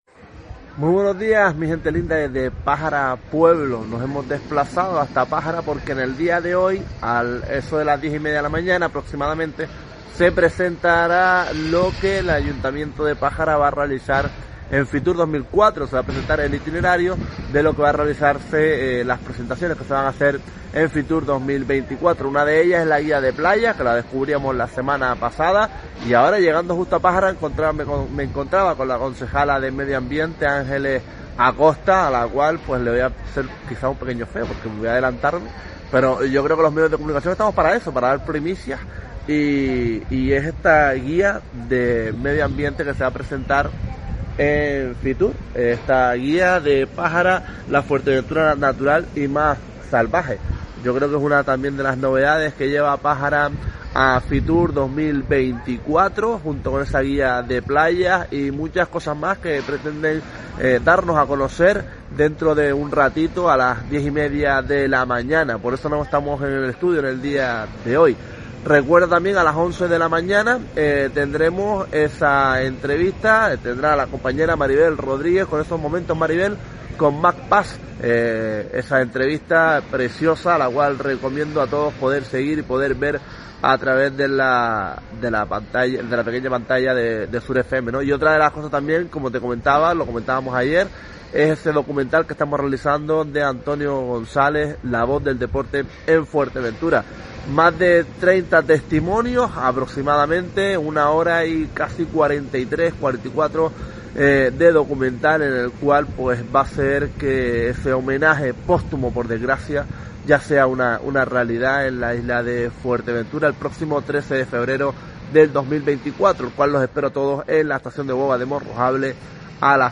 Nos desplazamos a Pájara Pueblo para cubrir la rueda de prensa de presentación que el Ayuntamiento de Pájara llevará a Fitur 2024.